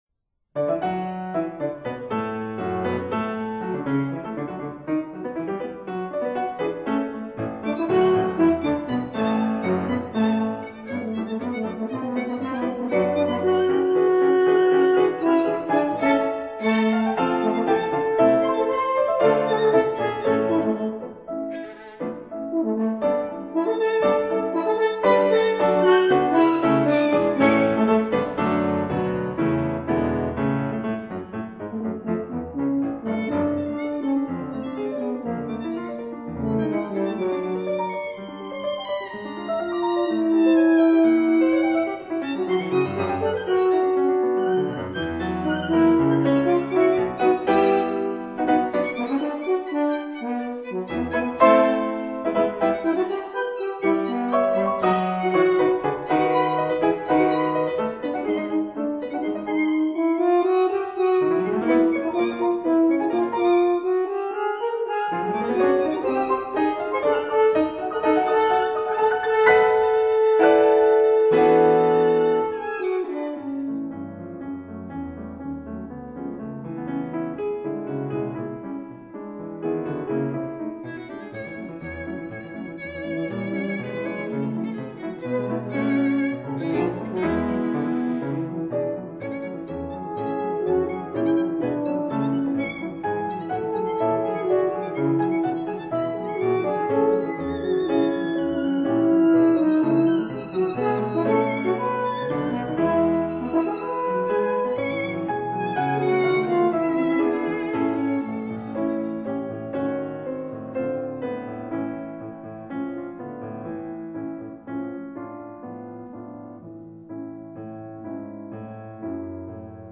violin
horn
piano